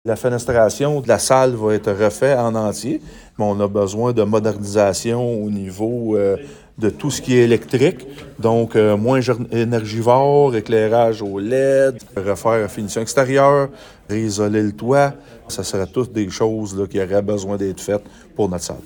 Celle-ci comprend le projet du garage municipal et la rénovation du centre communautaire. Le maire, Mathieu Caron, parle plus en détail des travaux qui seront entrepris au centre :